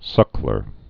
(sŭklər)